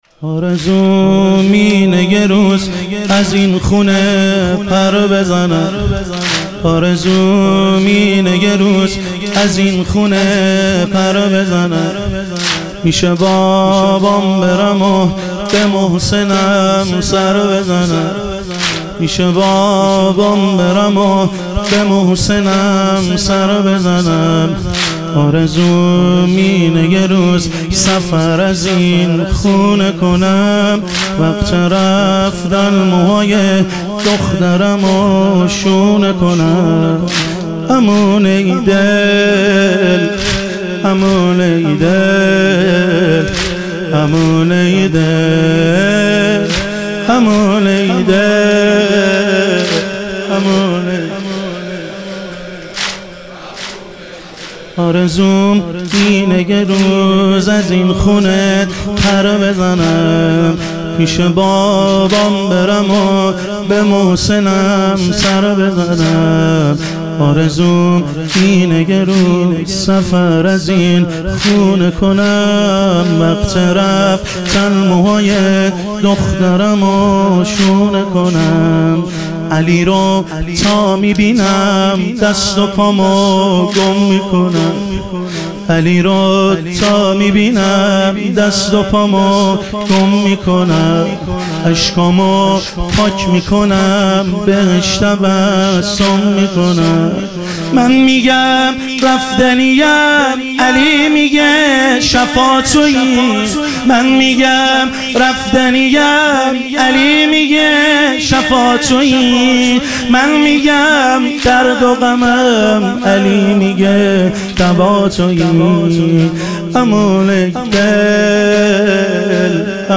ایام فاطمیه اول - واحد